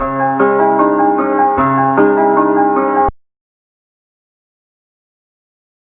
Sizzle keys.wav